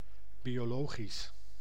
Ääntäminen
IPA: /ˌbijoˈloːɣis/